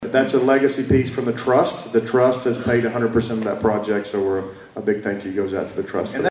During last week’s State of the Cities and County Address, Madisonville Mayor Kevin Cotton and Hopkins County Judge-Executive Jack Whitfield shared updates on local projects, including the addition of the new extension office at Mahr Park Arboretum.